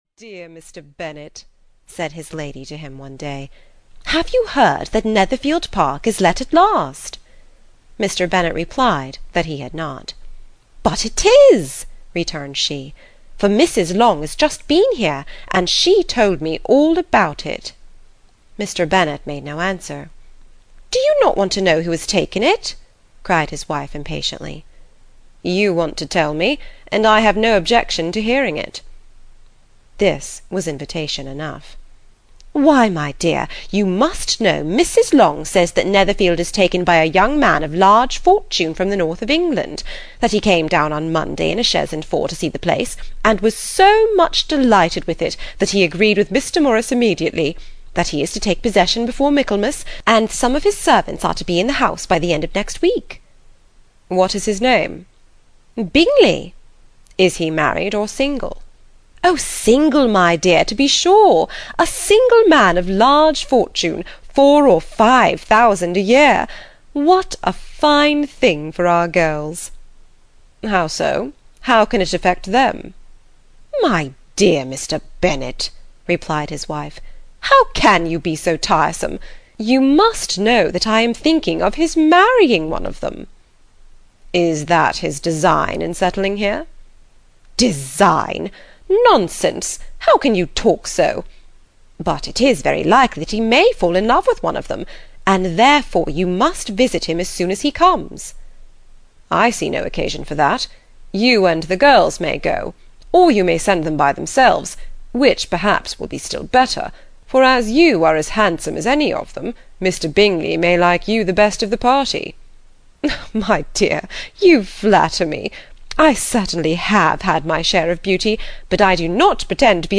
Pride and Prejudice (EN) audiokniha
Ukázka z knihy